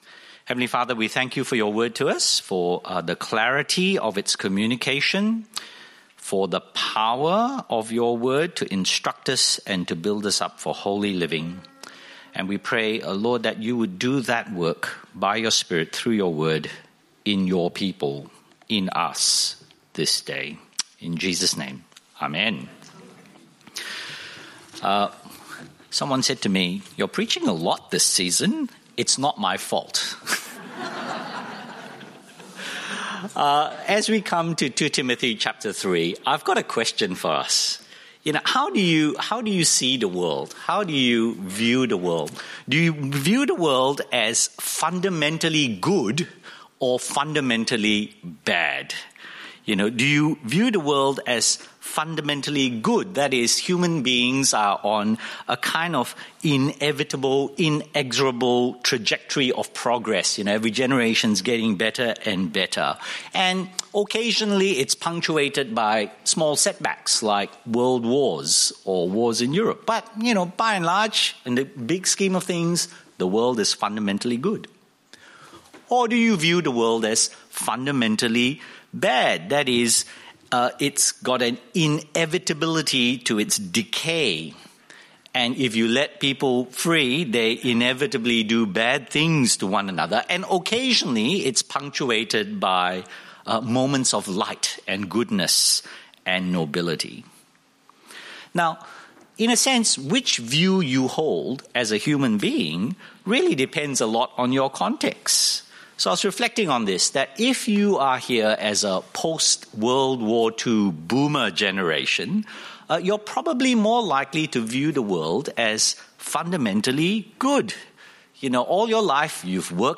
The bible reading is 2 Timothy 3:1-9.